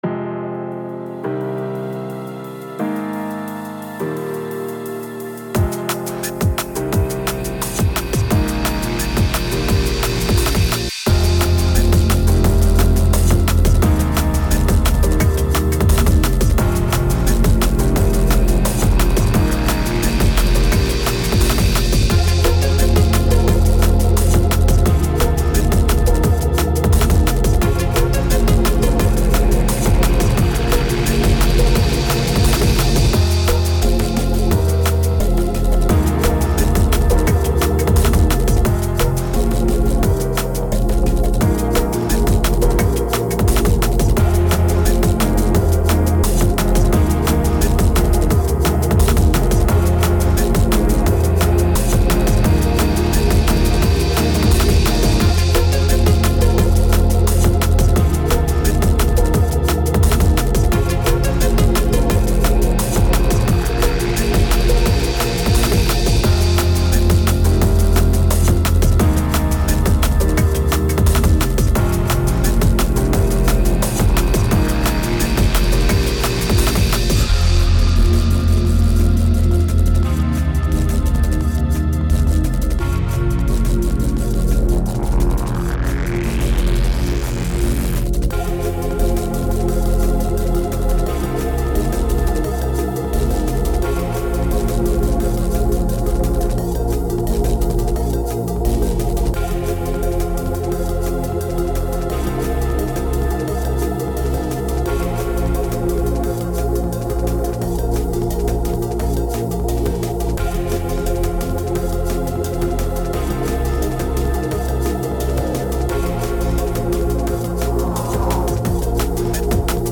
Tempo 174BPM (vivace)
Genre Dystopian Drum And Dub-bass
Type adlib-strumental
Mood conflicted [Energetic/Aggressive]
Render Loudness -6 LUFS /// Bass Boosted